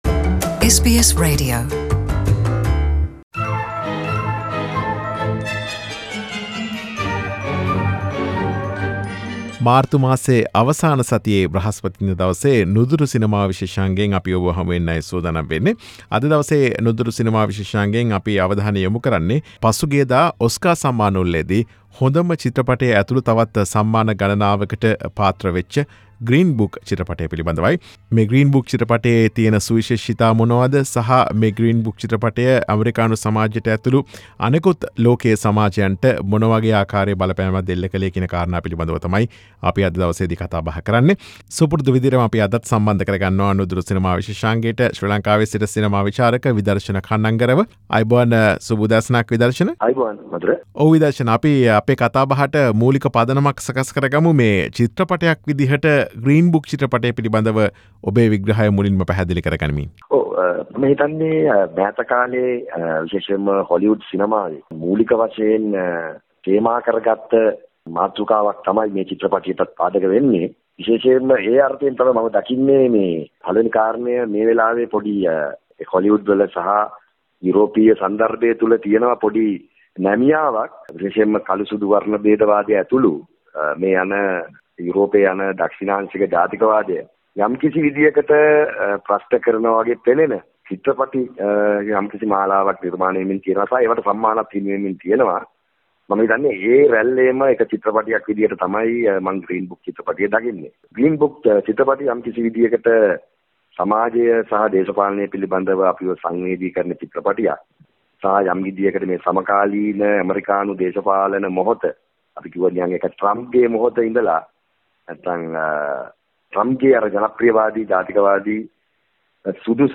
අප සමග කතා බහට